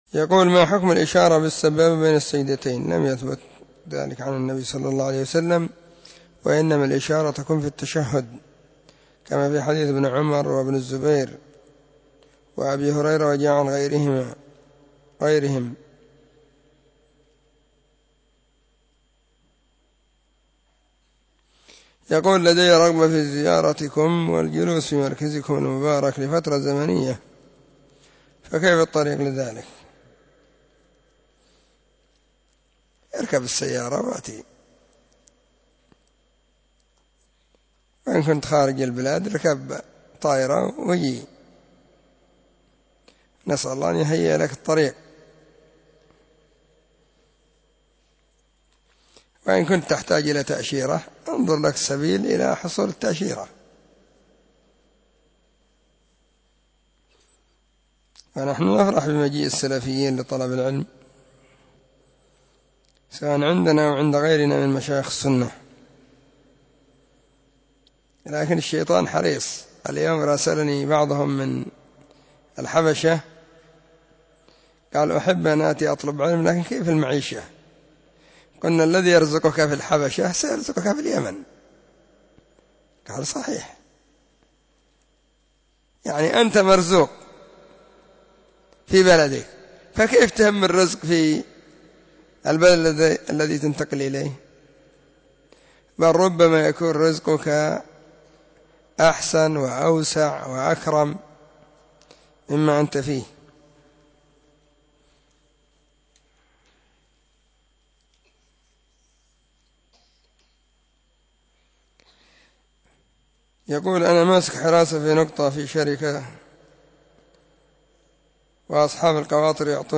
🔹 سلسلة الفتاوى الصوتية 🔸الاثنين 18 /ذو القعدة/ 1442 هجرية.